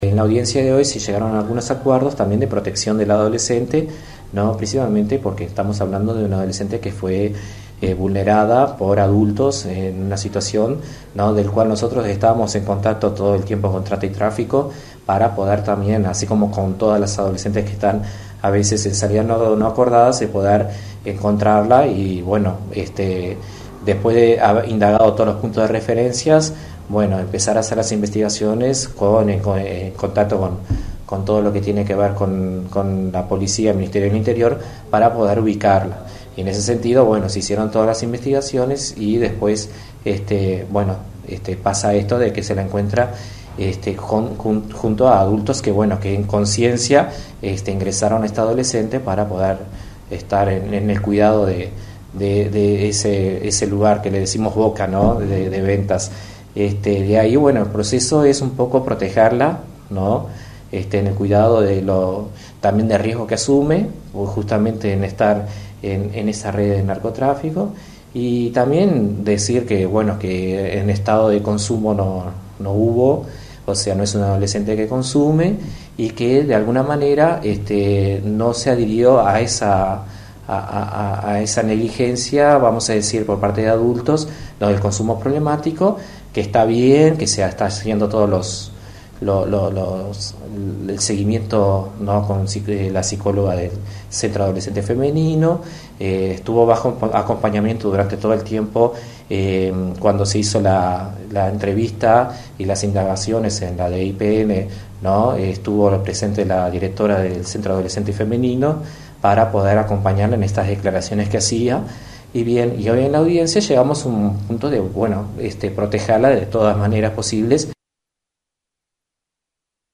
Según informó este martes el director departamental de INAU, Marcio Pintos, la menor estaba junto a cuatro adultos cuando la Policía ingresó en la vivienda.